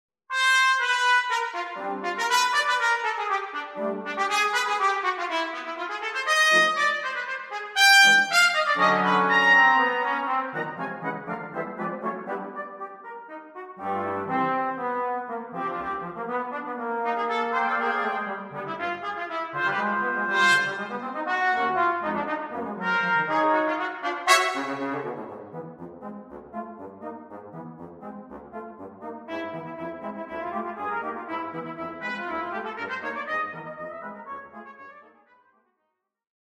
Brass Ensemble
• 3 Trumpets in B flat
• 2 Trombones
• Bass Trombone